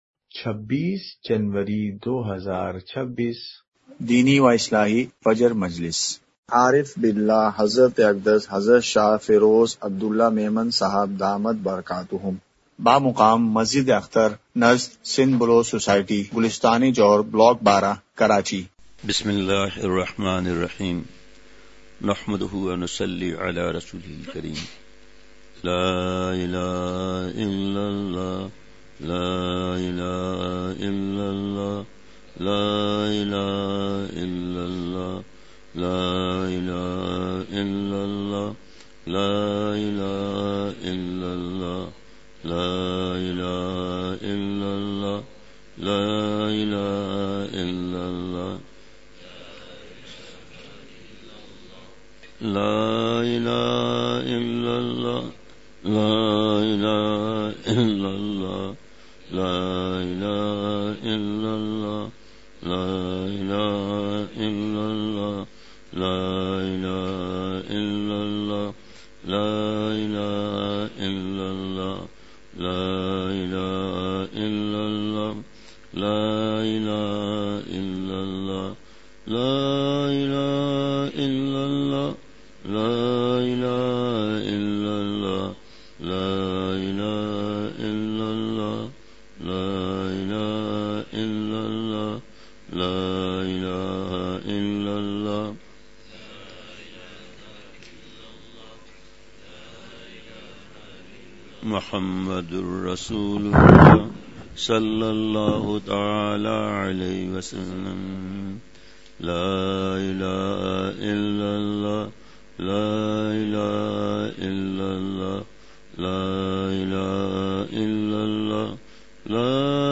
اصلاحی مجلس
*مقام:مسجد اختر نزد سندھ بلوچ سوسائٹی گلستانِ جوہر کراچی*